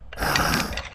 PixelPerfectionCE/assets/minecraft/sounds/mob/stray/idle3.ogg at mc116